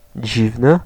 Ääntäminen
US : IPA : /ɑd/ UK : IPA : /ɒd/